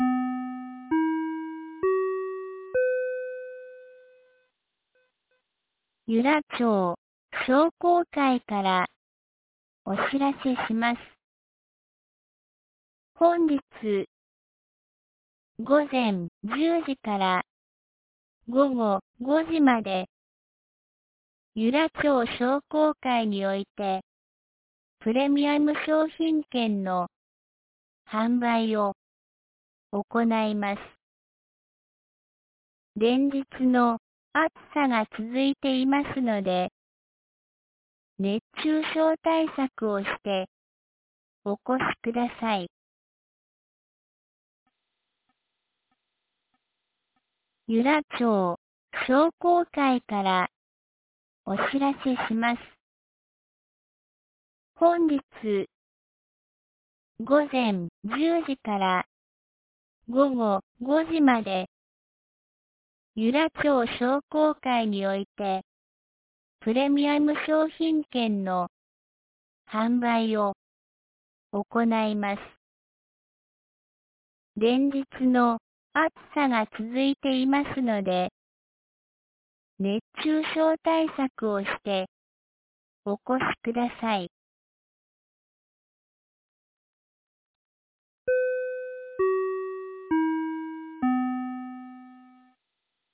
2025年08月31日 07時52分に、由良町から全地区へ放送がありました。